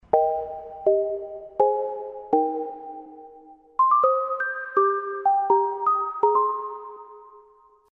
Descarga de Sonidos mp3 Gratis: sinfonia tono.
symphony-tone-long-ringtones.mp3